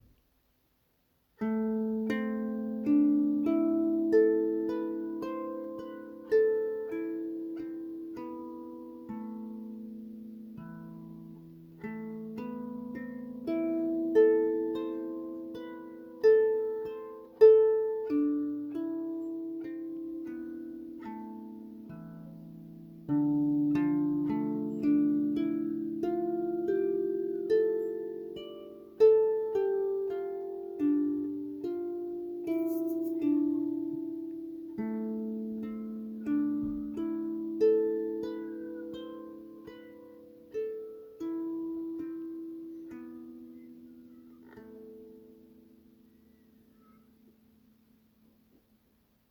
HS 7016 Harpschaal
Deze HS 7016 heeft een harpstemming vanaf C3 tot de D5.
inzetbaar voor begeleiding en meditatieve muziek. Door het bereik van ruim twee octaven is muzikaal veel mogelijk.
mooi eigen geluid, dat goed aansluit bij het gebruik van klankschalen.